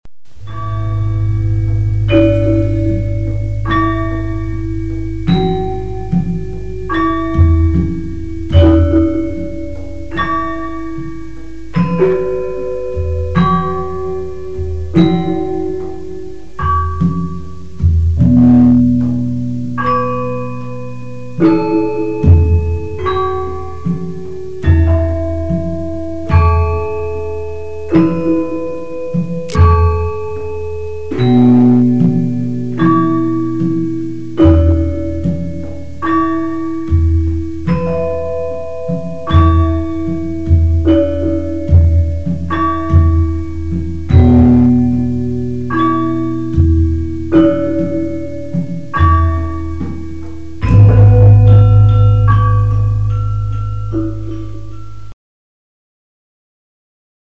Javansk karawitan
Balungan (kernemelodien spillet på saron, peking og slentem, akkompagneret af kolotomiinstrumenter.